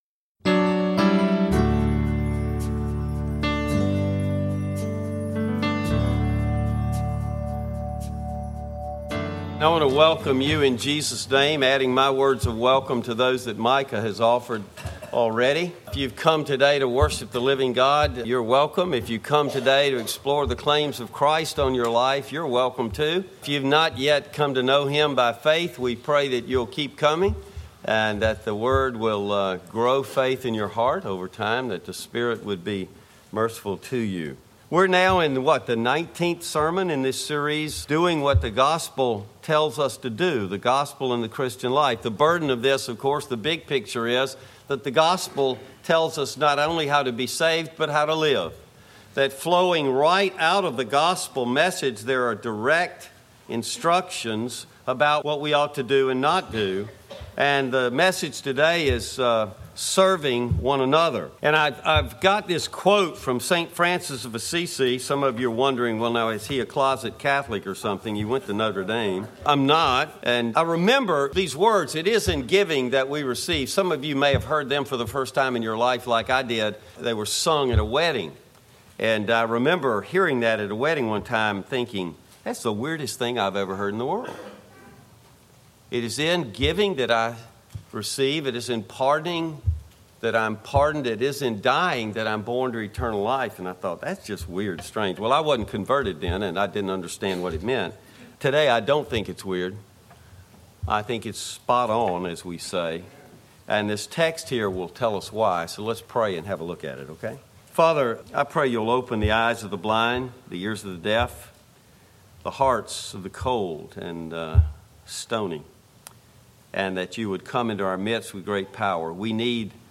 20-28 Sermon audio will be posted shortly.